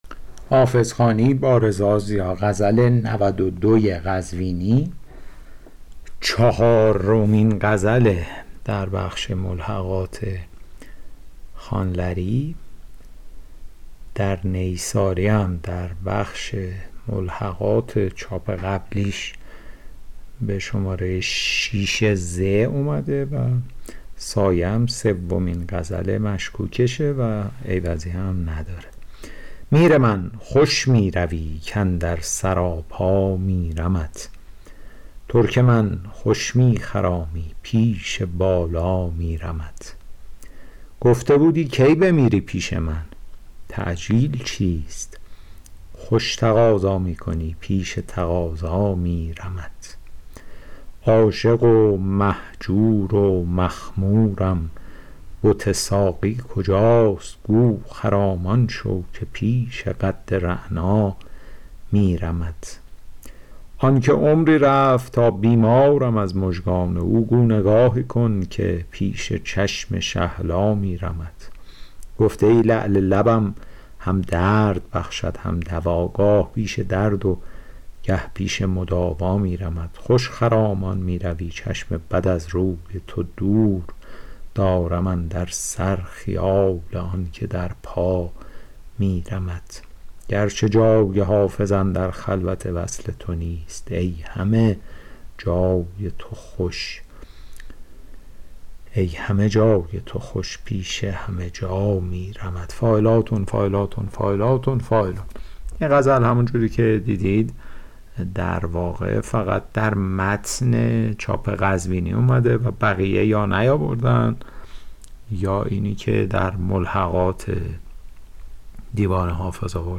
حافظ غزلیات شرح صوتی